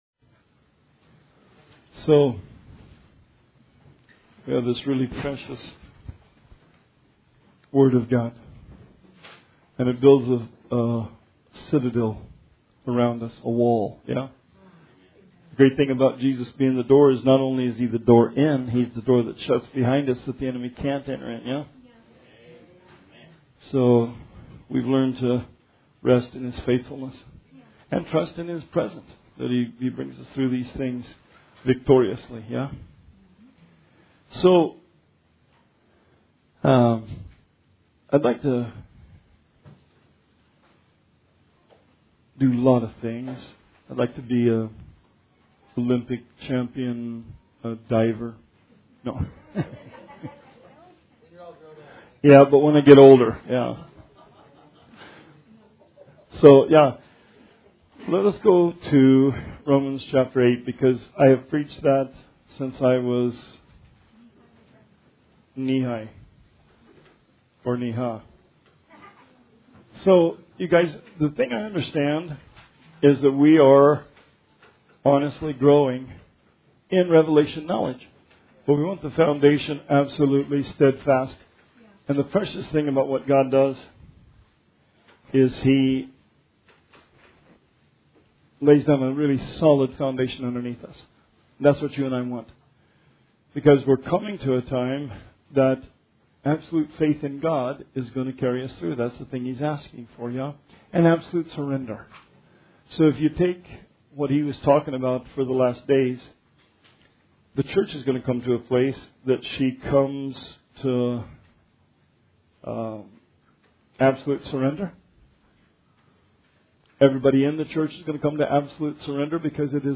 Bible Study 9/18/19 – RR Archives